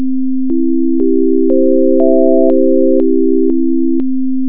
First, let’s test out our class’ ability to add tones to a waveform; we will add the tones of the C chord on a guitar in a progressive fashion. The tones will add together to and then take turns fading away to return to the original C note.
cchord.wav